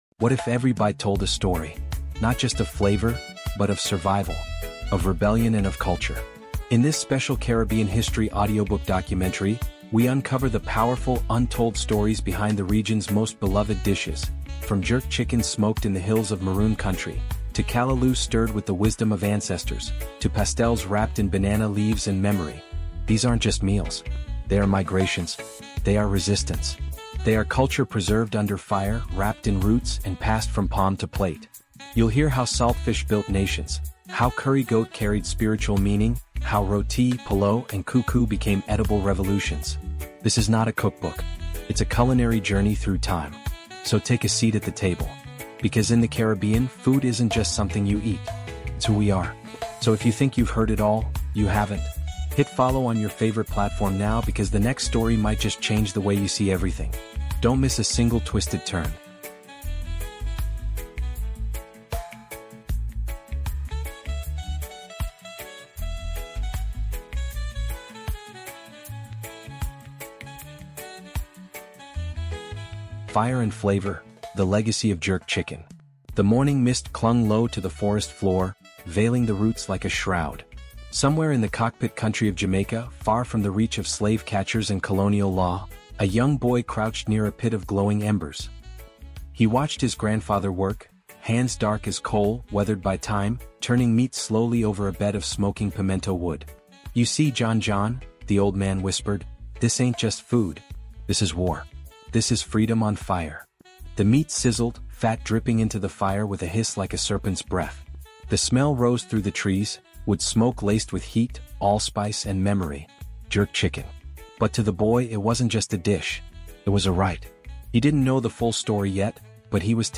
This immersive Caribbean History audiobook documentary takes you beyond recipes and deep into the cultural heart of the region’s most iconic foods — each dish a living story of resilience, survival, and culinary brilliance.